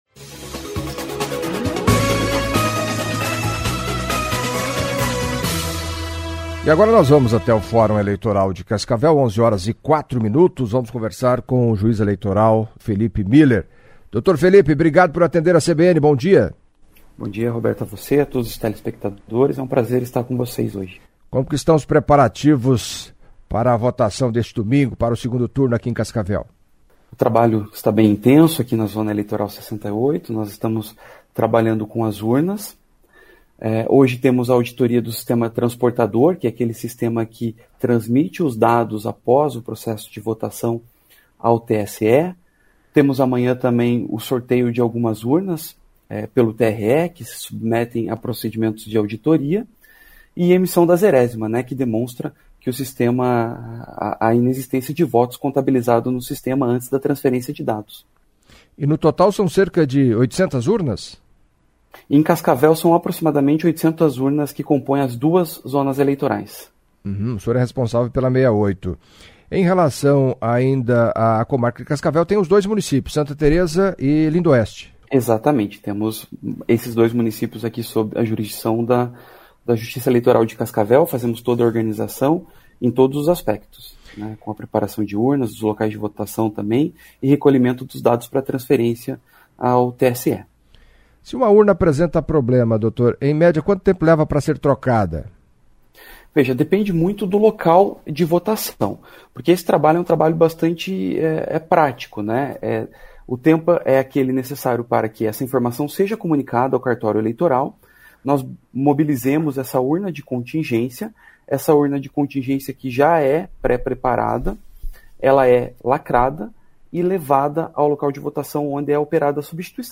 Em entrevista à CBN Cascavel nesta sexta-feira (28) o juiz eleitoral, Phellipe Muller, detalhou o andamento dos trabalhos e a expectativa que ele tem para o processo de votação no domingo em Cascavel.